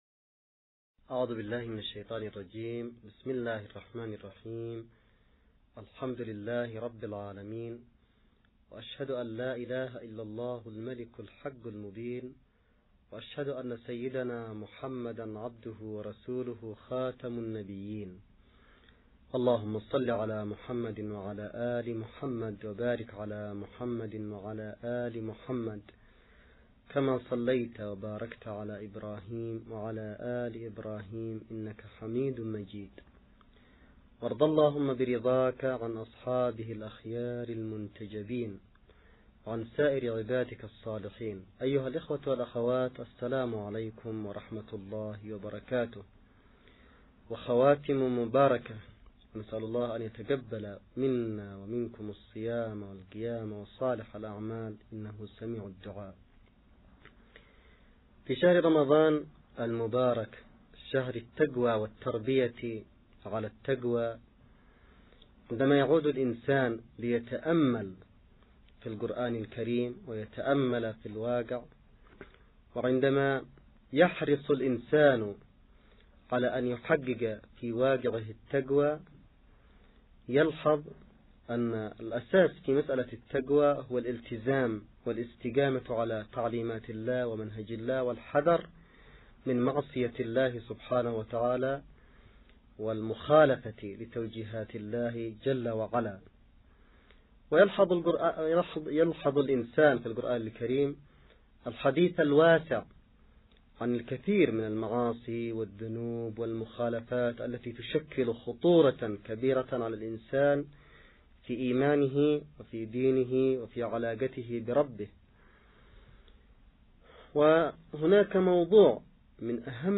نص+أستمع للمحاضرة الرمضانية للسيد عبدالملك بدرالدين الحوثي بعنوان خطورة النفاق